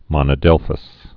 (mŏnə-dĕlfəs, mōnə-)